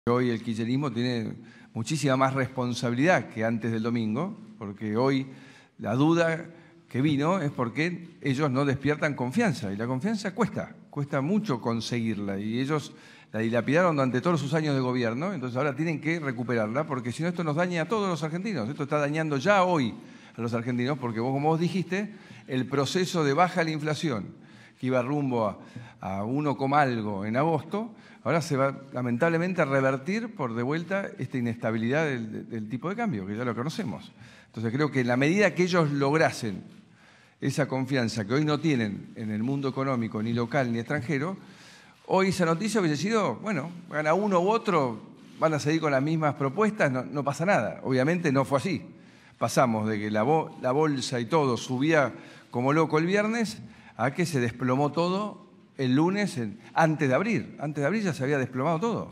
El Presidente Mauricio Macri abrió una conferencia de prensa el lunes y citando a uno de los autores predilectos que estudian de la carrera de Psicología de la UBA (perdón al oficialismo por mencionar una pública), la tesis lacaniana dice que “la responsabilidad es el castigo”.